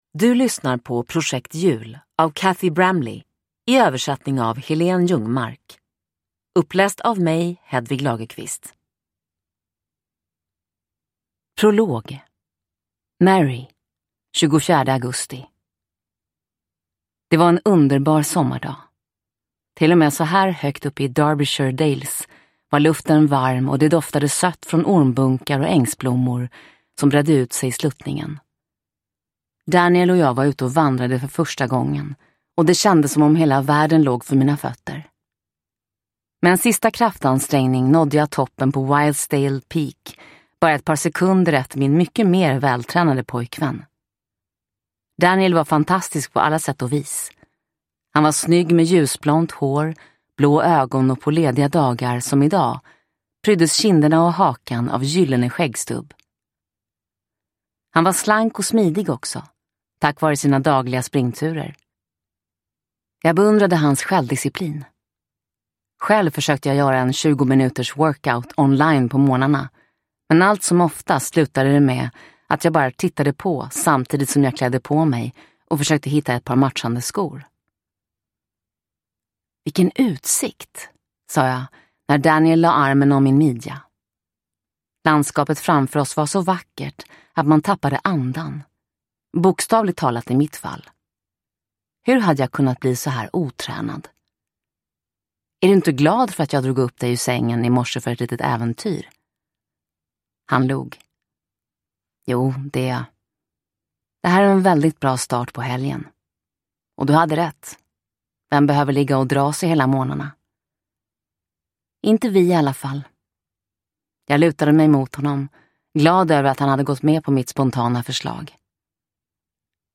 Projekt jul – Ljudbok – Laddas ner